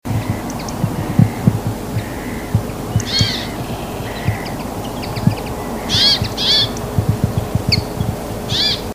Trekroepjes Vinken